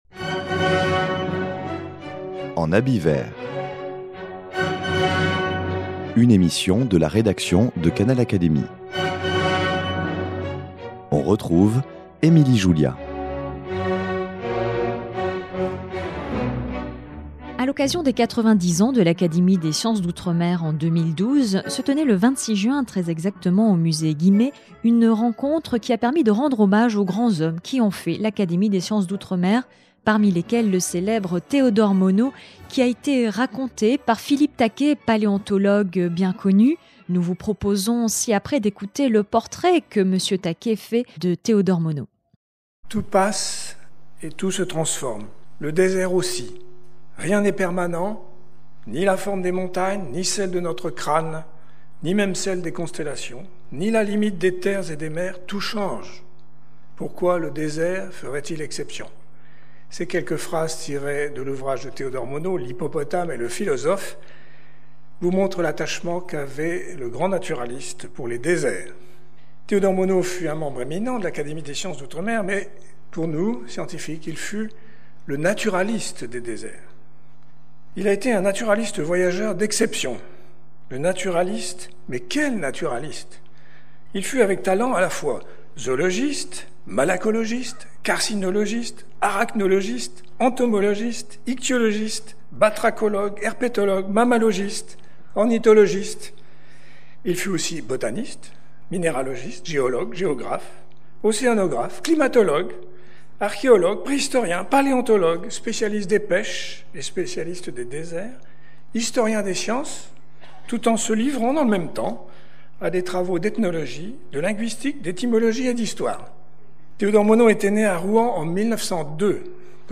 Théodore Monod est ici raconté par le paléontologue Philippe Taquet.
Un portrait tout en humour prononcé à l’occasion des 90 ans de l’Académie des sciences d’outre-mer.
L’après-midi était consacrée à un hommage à l'Académie et aux hommes qui l'ont faite, parmi lesquels Théodore Monod.